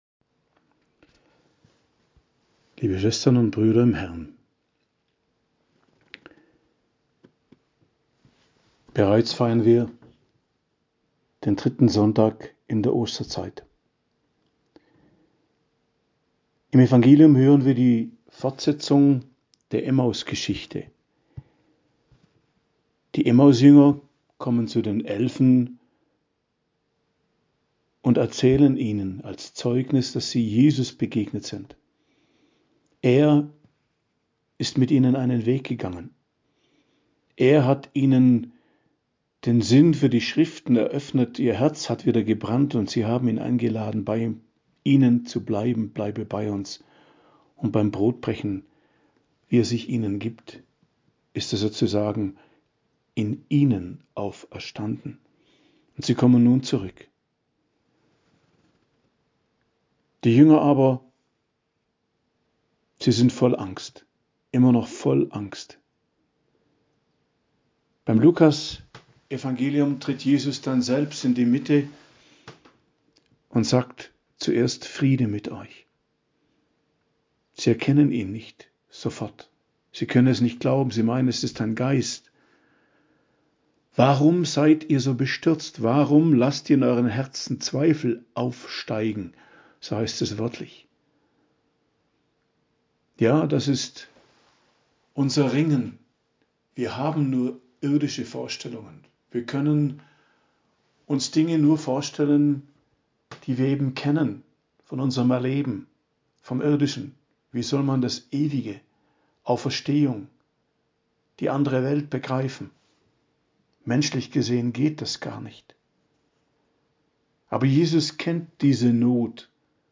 Predigt zum 3. Sonntag der Osterzeit, 14.04.2024 ~ Geistliches Zentrum Kloster Heiligkreuztal Podcast